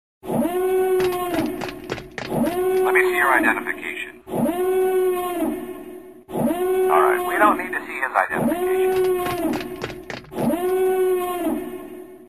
Play, download and share ID_Alert original sound button!!!!
resident_id_sub_alert.mp3